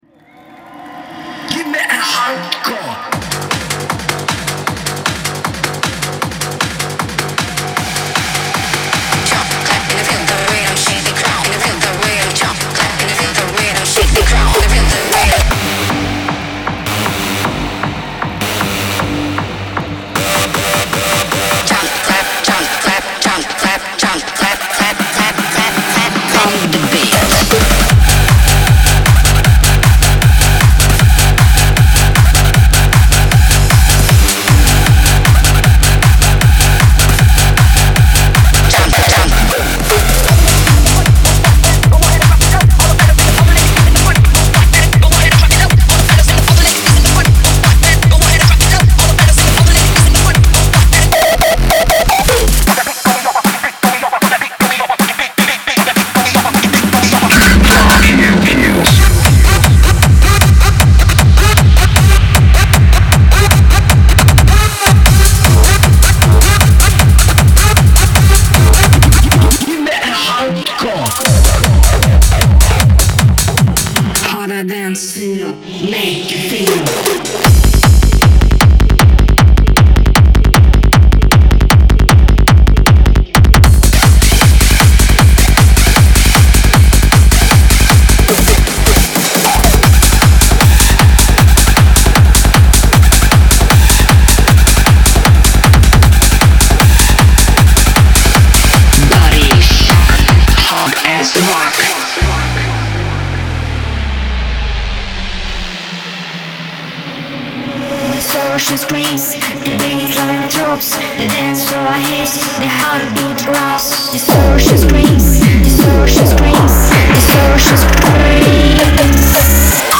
Genre:Hard Dance
60 Bass Loops – 強烈なオフビートベースラインから歪んだサイベースグルーヴまで。
99 Synth Loops – 荒々しいリードと歪んだスクリーチ（ウェット＆ドライ）。
123 Vocals – クラウドシャウトからグリッティなライム＆フレーズまで。
生で、力強く、ピークタイムの混沌のために作られています。
デモサウンドはコチラ↓
155, 160 BPM